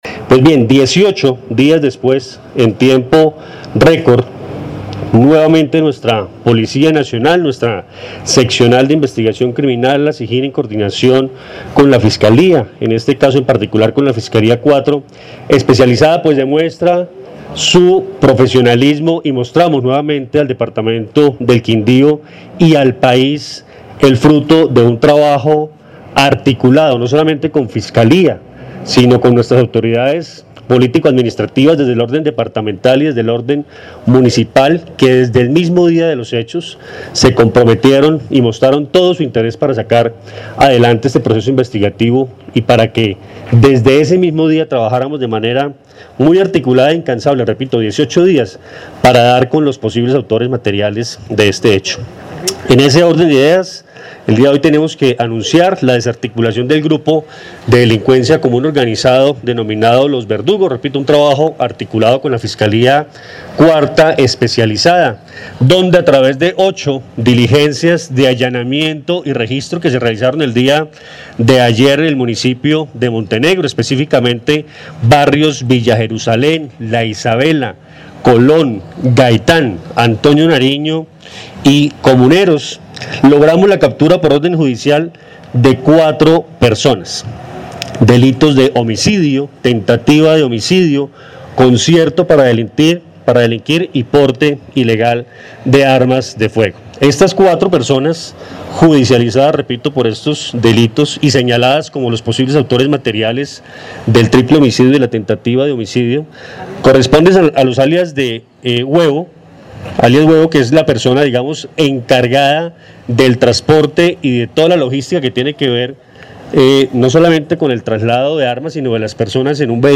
Coronel Luis Fernando Atuesta, capturas por masacre en Armenia